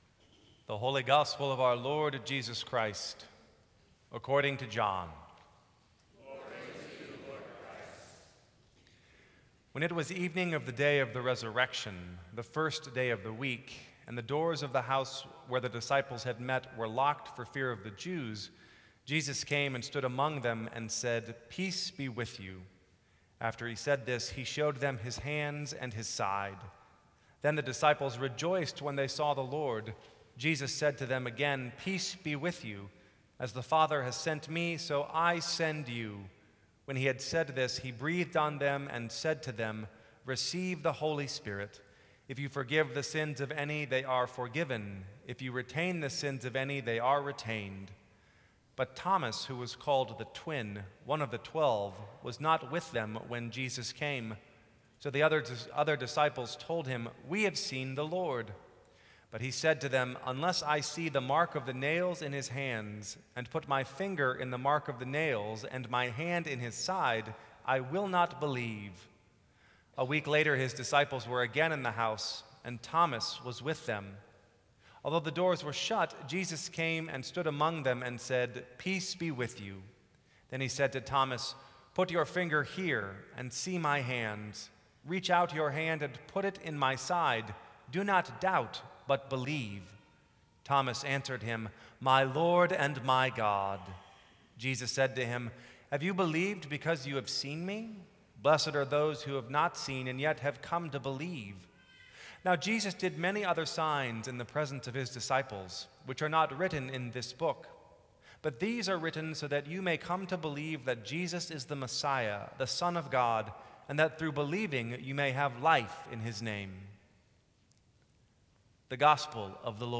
Sermons from St. Cross Episcopal Church April 27, 2014.